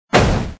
TrafficCollision_3.mp3